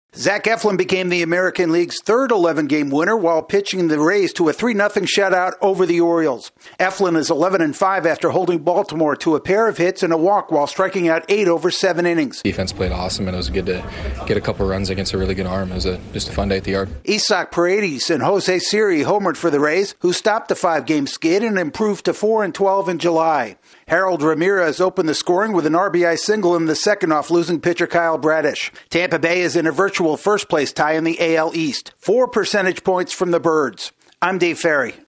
The Rays halt their skid with a well-pitched game against the Orioles. AP correspondent